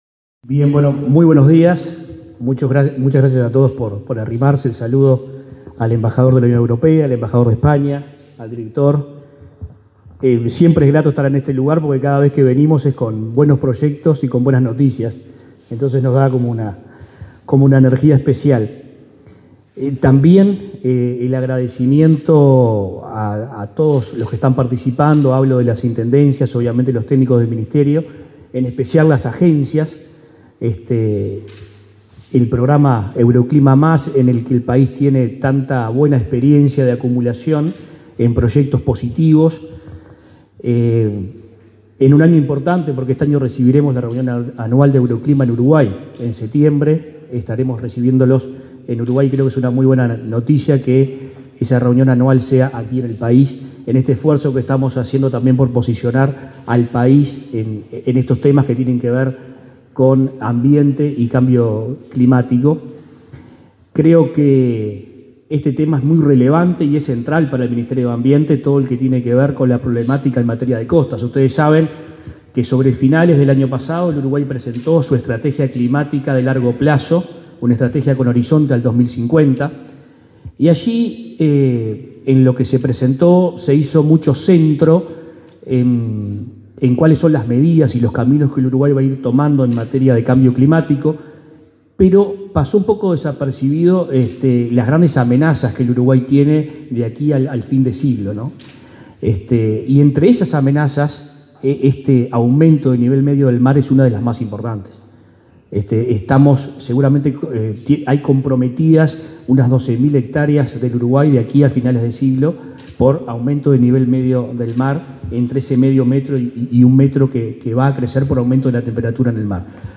El ministro de Ambiente, Adrián Peña, presentó este martes 5 el Plan Nacional de Adaptación al Cambio Climático para la Zona Costera, acto en el que